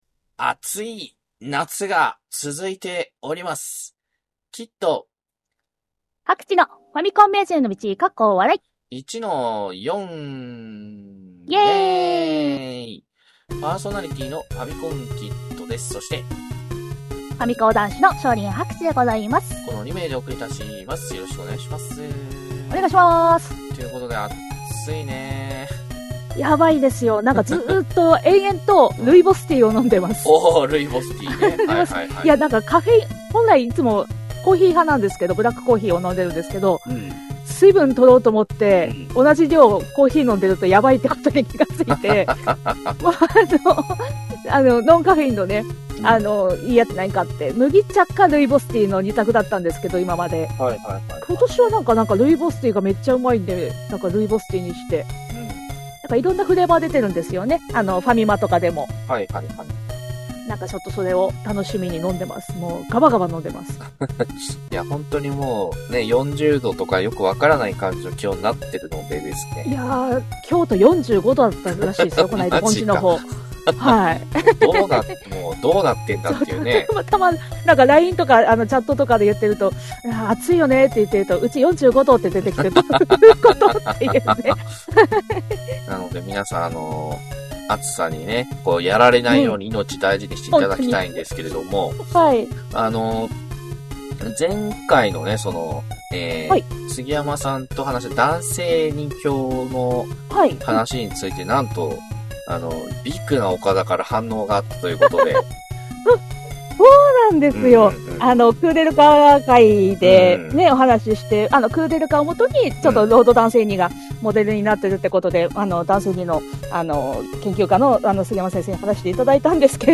昭和っぽいテイストのインターネットラジオ